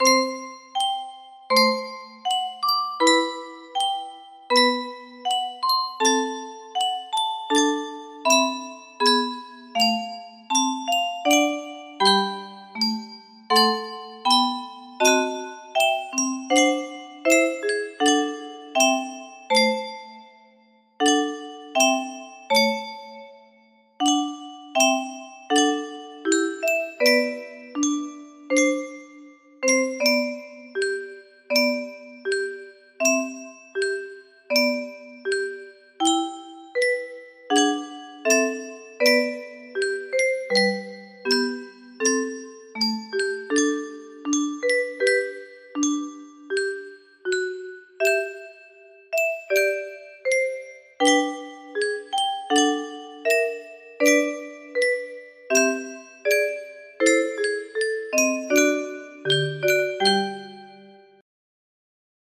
Romance 1 music box melody